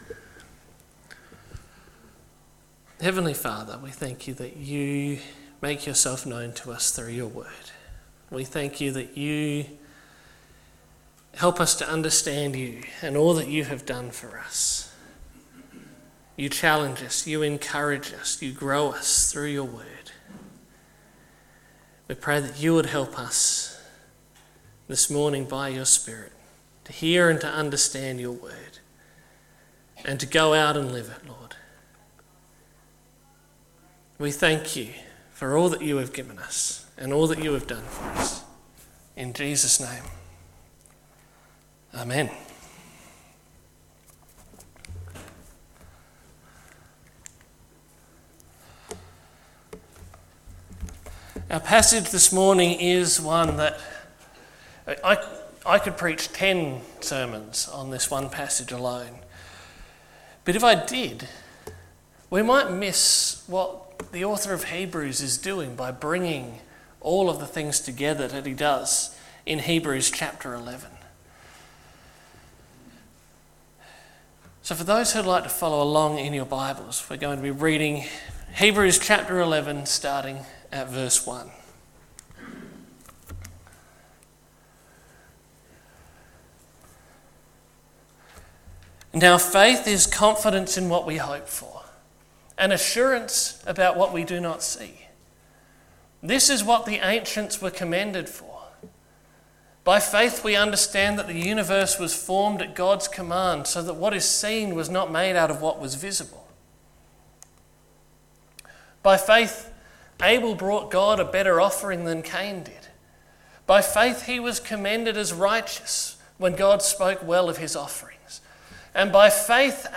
Sermons by Birdwood United Church